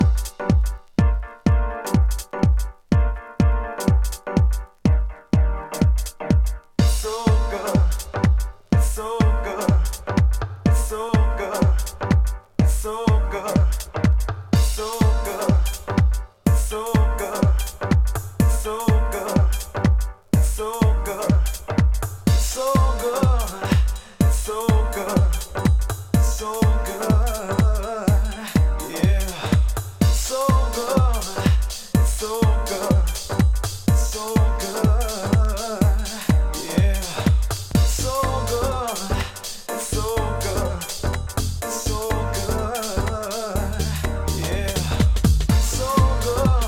ヴォーカル入りのElectro～Tech House
ズッシンズッシン重いビートが腰にクル！